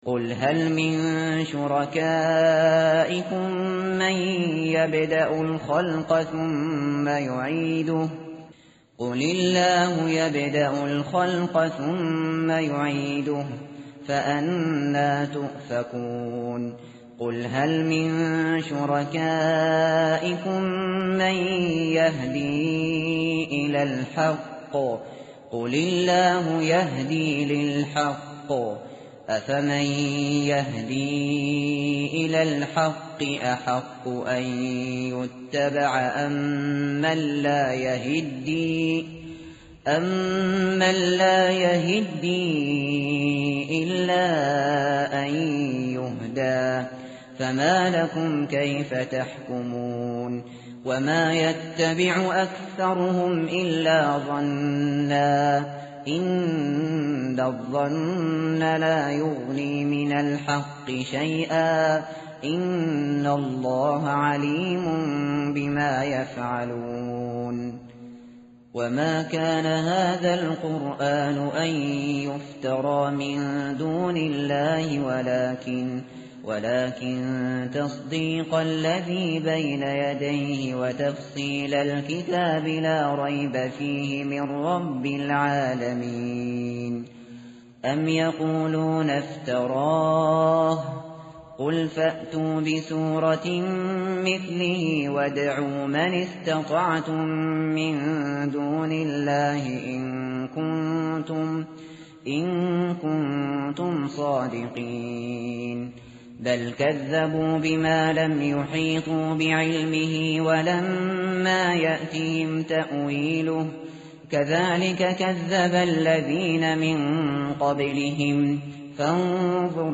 tartil_shateri_page_213.mp3